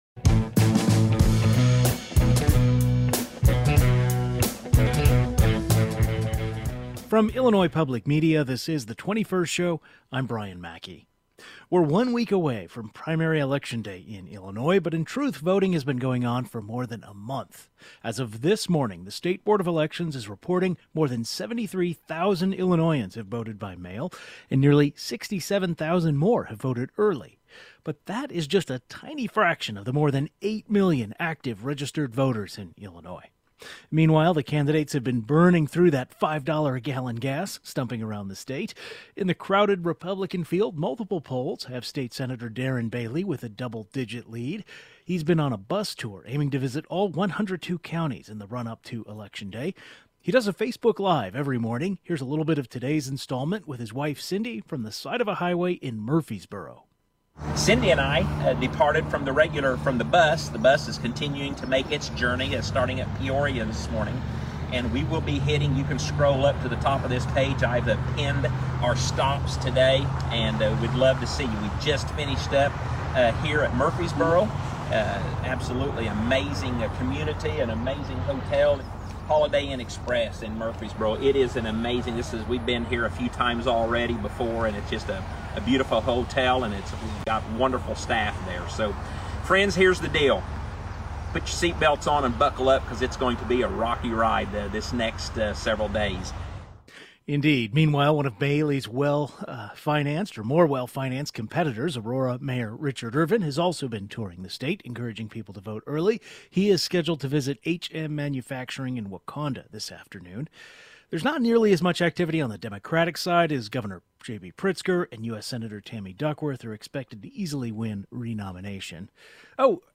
With one week to go until the June 28, 2022 primary election, we're talking with politics reporters abuut the status of campaigning and early voting.